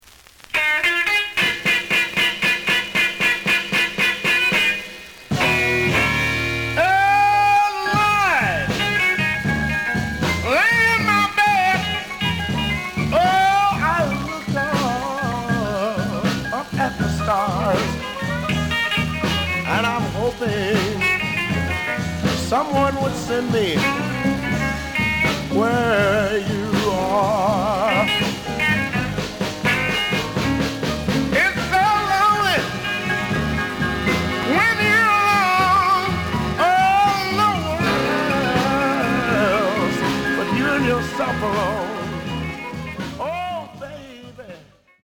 試聴は実際のレコードから録音しています。
The audio sample is recorded from the actual item.
●Genre: Blues